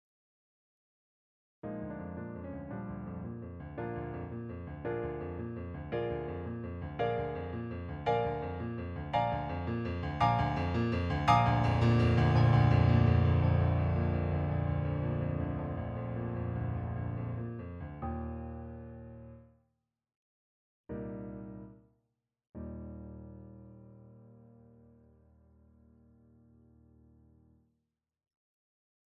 Kafka page 228 - Finale, peut-être pour piano.
Les indications dynamiques "p", "ff", "fff", "pp", "ppp", "Schluss" et "descrescendo" sont originaux dans le texte.
Kafka_Pagina228_FinaleForsePerPianoforte.mp3